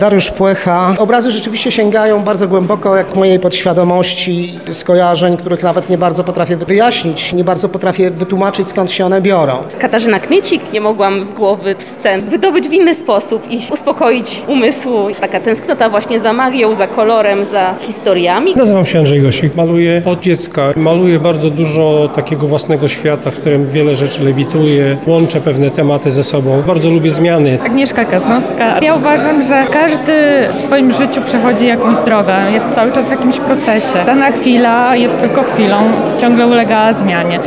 Więcej na temat prac i o procesie twórczym mówią sami artyści: